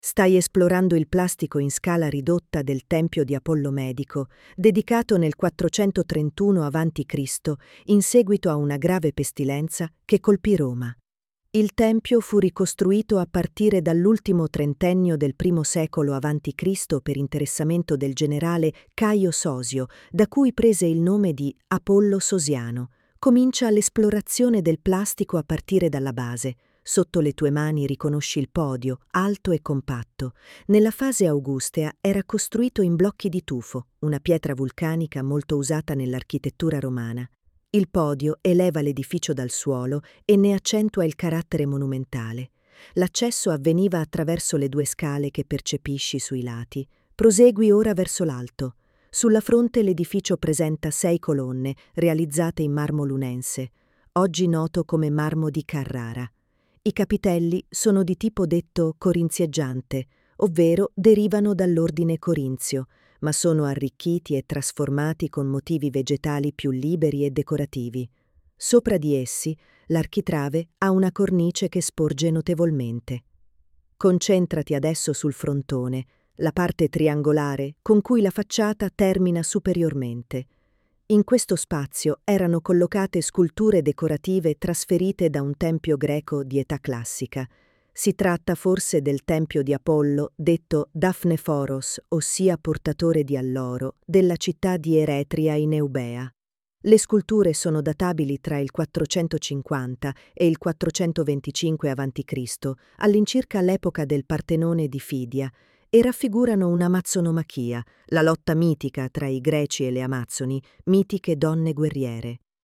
•    9 AUDIODESCRIZIONI che accompagnano il visitatore nell’esplorazione delle opere, ognuna indicata da didascalia in Braille e guida audio, con il relativo testo, fruibile tramite QR code: